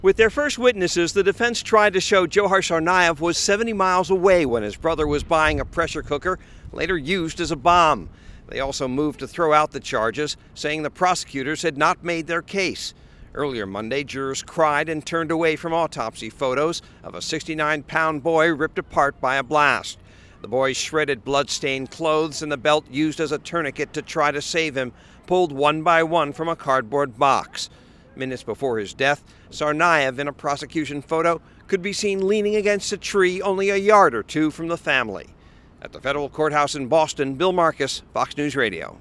LIVE FROM THE FEDERAL COURTHOUSE IN BOSTON:
4PM LIVE –